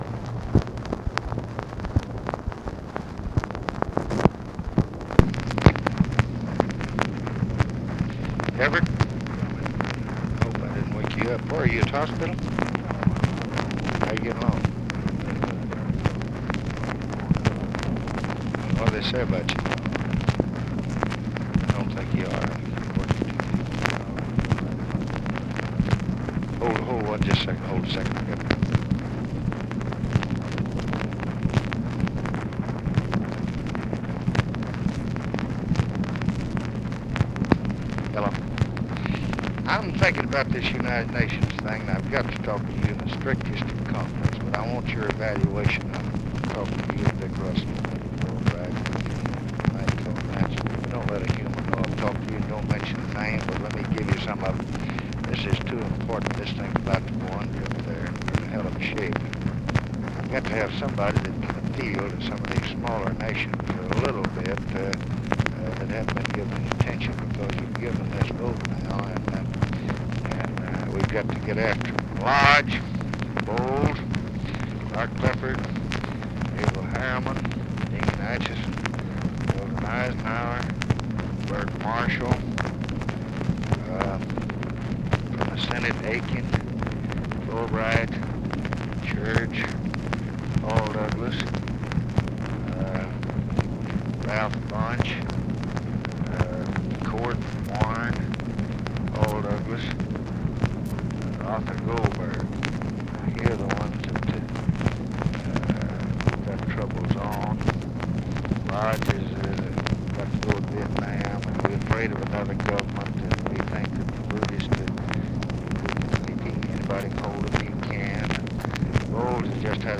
Conversation with EVERETT DIRKSEN, July 20, 1965
Secret White House Tapes